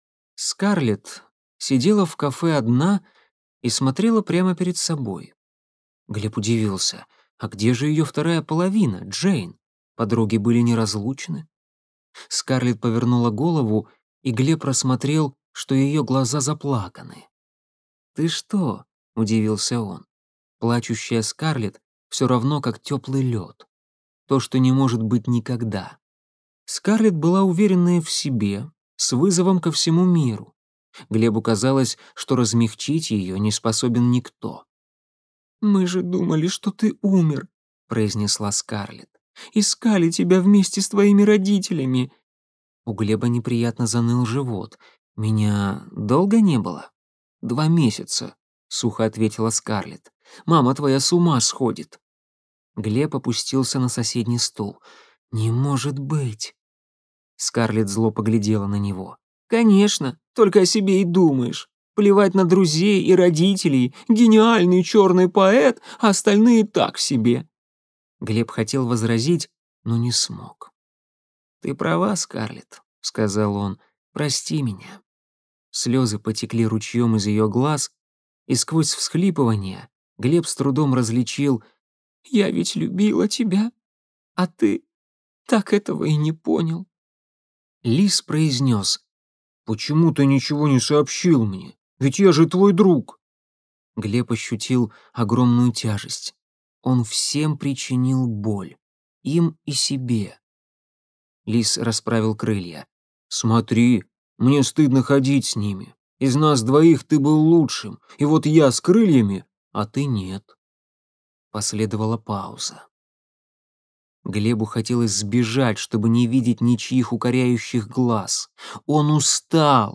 Аудиокнига Темногорье. Яблоневая долина | Библиотека аудиокниг